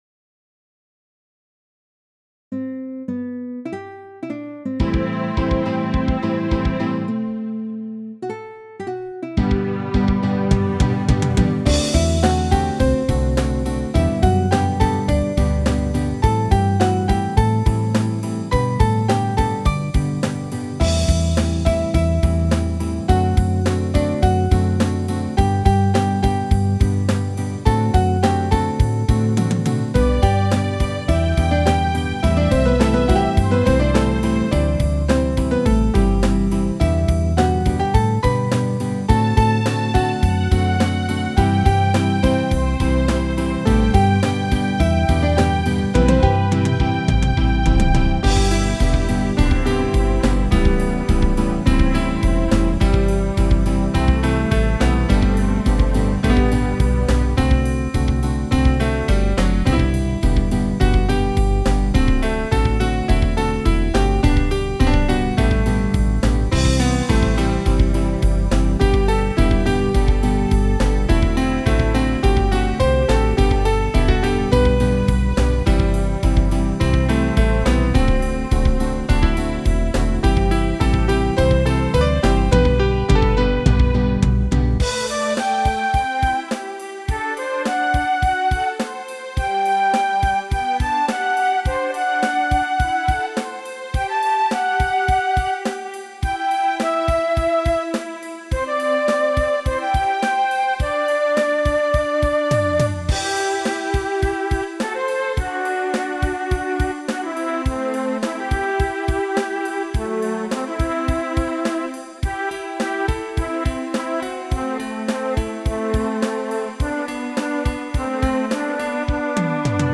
ピアノ・フルート・ギター・ストリングス他